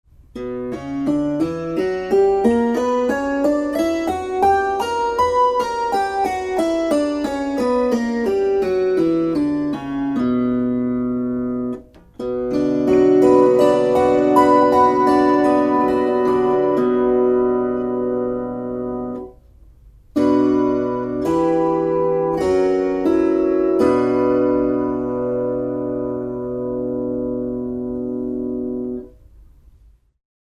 Kuuntele h-molli. ais fis cis Opettele duurit C G D A E F B Es As mollit a e fis cis d g c f Tästä pääset harjoittelun etusivulle.
hmolli.mp3